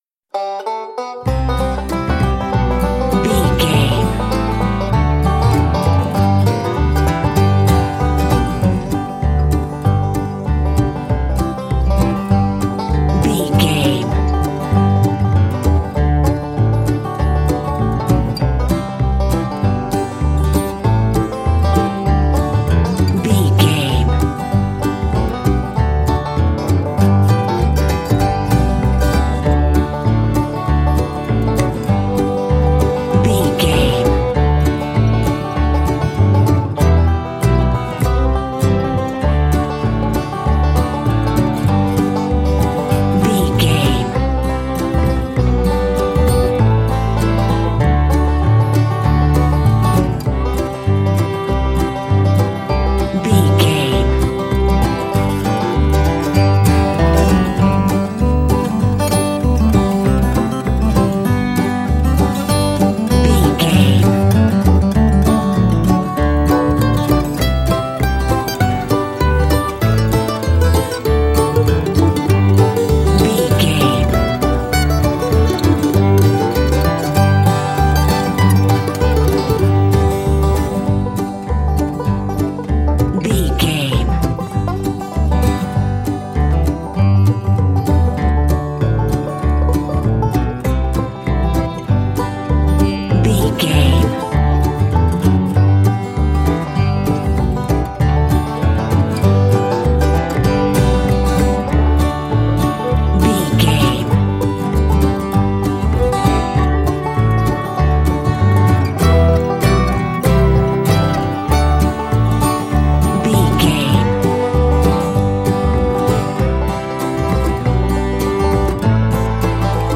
Ionian/Major
acoustic guitar
bass guitar
banjo